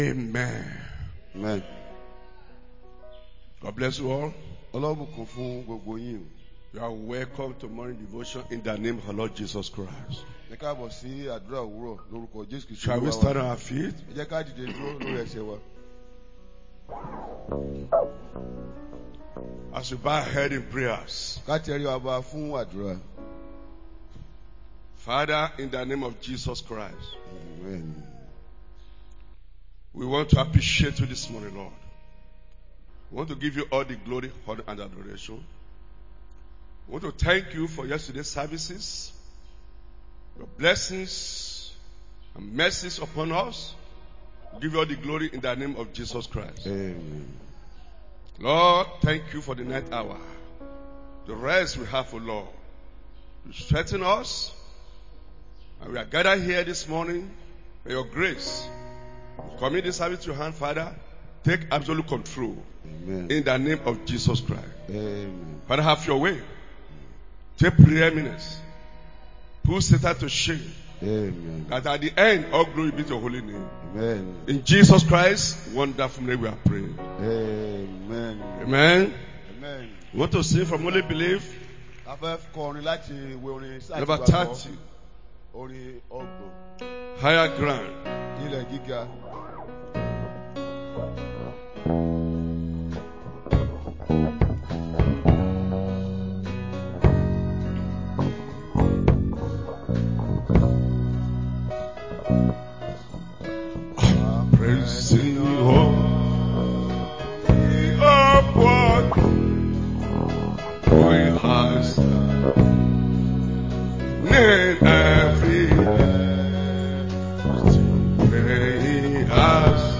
2025 Believers' Convention
Morning Devotion